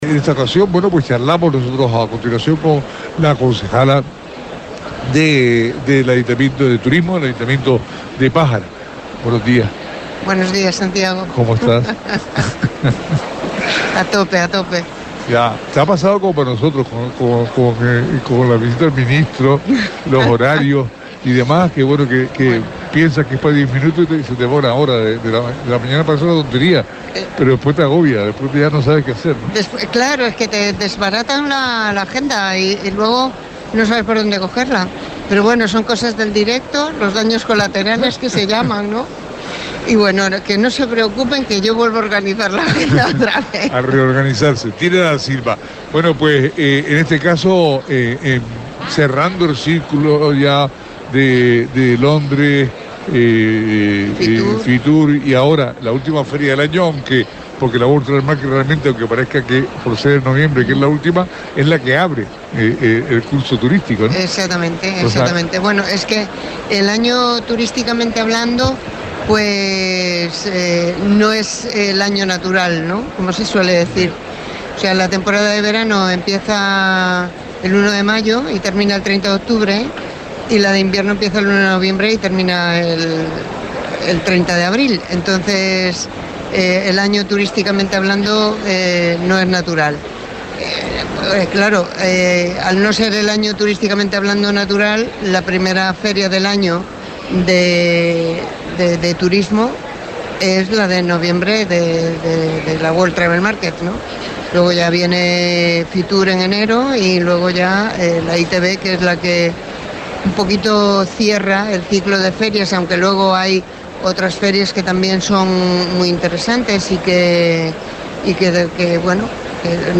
'Trabajamos para que Pájara se conozca como un destino de calidad'.Son palabras de la concejal de Turismo de Pájara, Tina Da Silva.
ITB Berlin 24